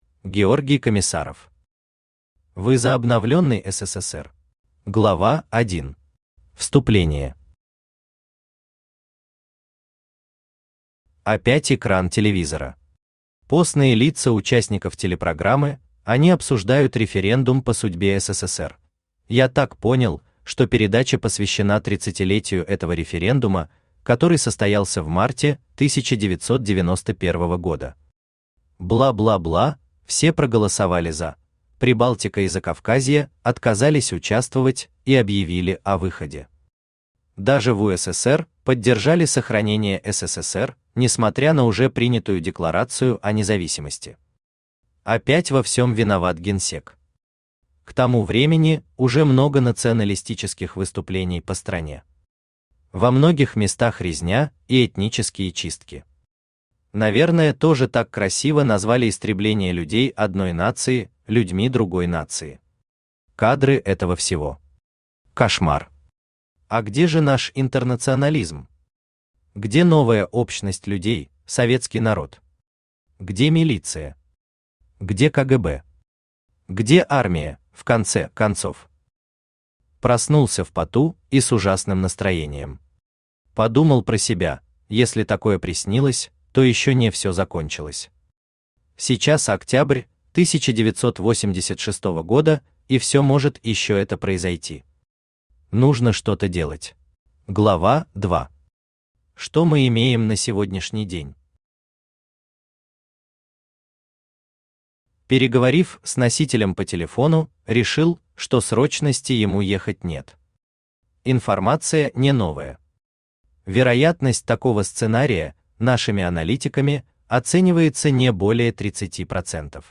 Автор Георгий Комиссаров Читает аудиокнигу Авточтец ЛитРес.